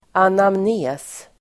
anamnes.mp3